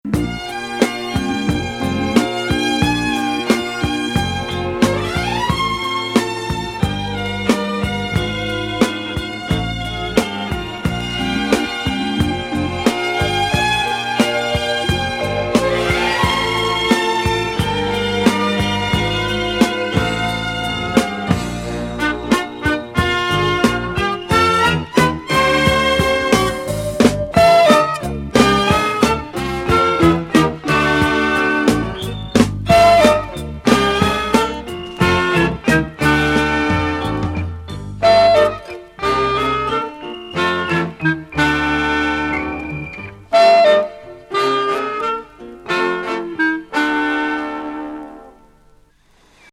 素晴らしいムードのチャンキー音楽。